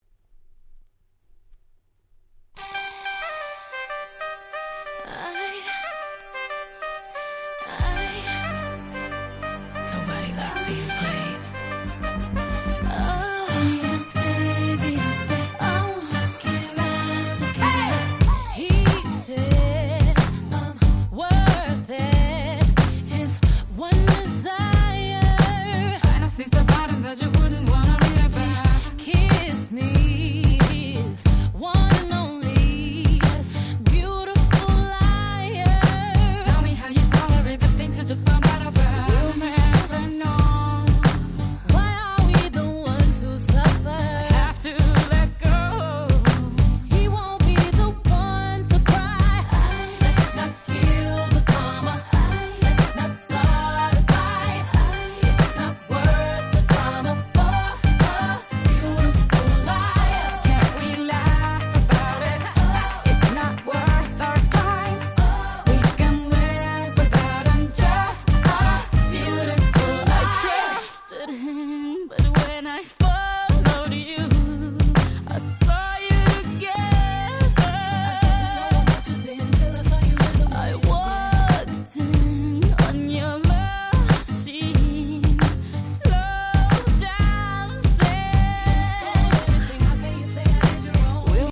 07' Super Hit R&B !!